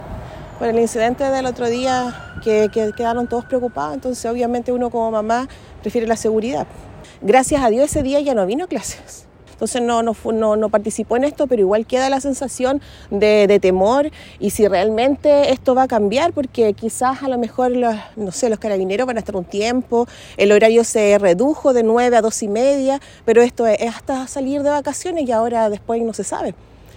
cuna-apoderado-retira.mp3